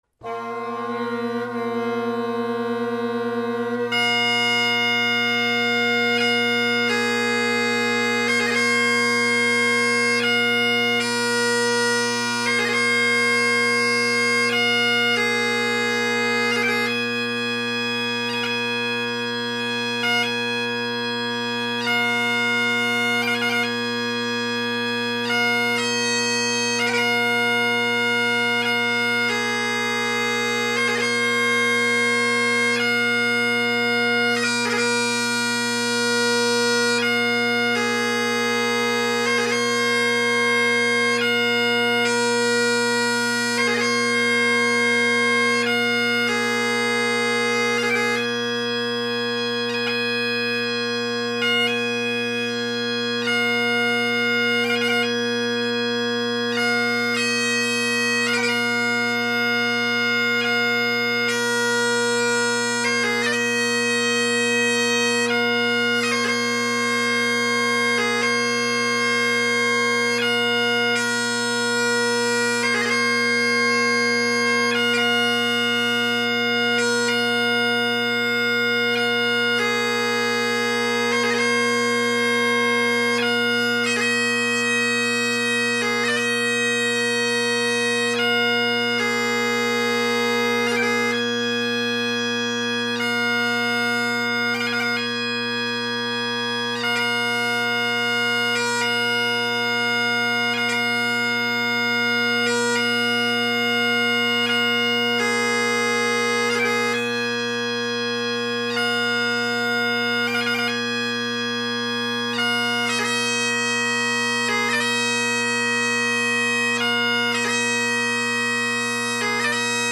In both cases, you’ll hear a marked improvement in tenor volume and overall blend.
For each recording in this post I start with a slow tune and face the mic with 4 different orientations that roughly change with the change in parts, taken in the following order: mic behind the drones, mic on my left (tenor side), mic chanter side, mic on my right (bass side); then I take a generic stance with the mic off to my left side (half way between mic chanter side and tenor side) for the faster tunes.